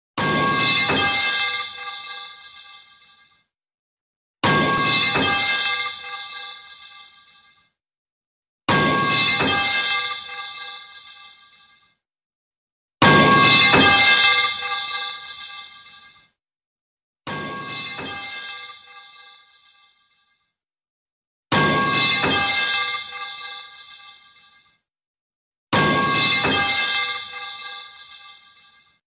I added the metal pipe sound effects free download